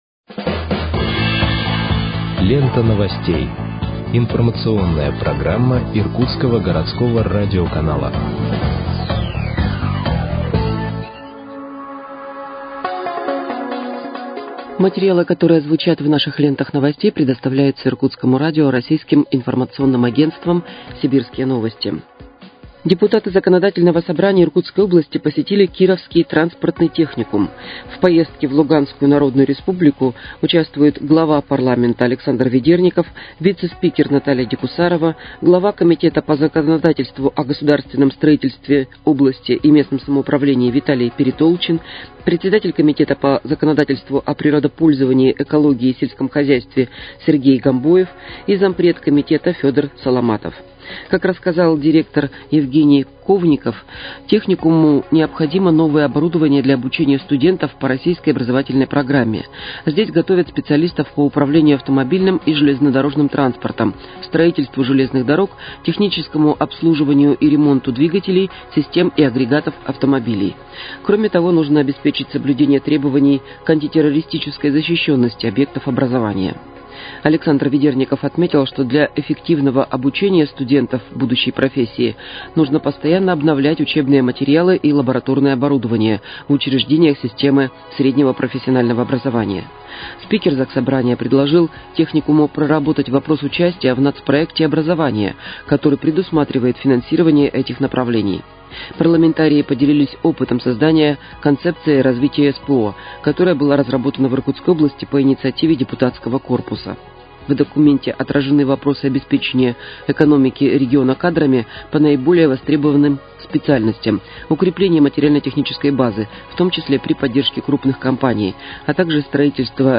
Выпуск новостей в подкастах газеты «Иркутск» от 2.06.2025 № 2